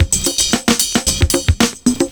112CYMB12.wav